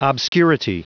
Prononciation du mot obscurity en anglais (fichier audio)
Prononciation du mot : obscurity